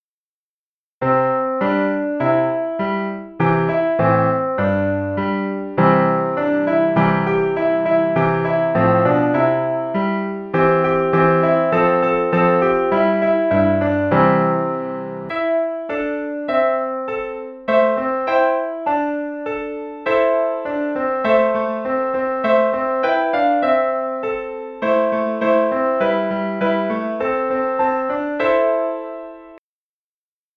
Piano keys are arranged symmetrically with a D key at the center.
Mirror and normal music ：
Japanese children song Hato-Pot・Pot
Mirrored music sounds somewhat odd when one listen to it for the first time but many people who listened to it several times gradually get used to it and start to love the mirrored sound.